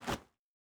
Jump Step Gravel A.wav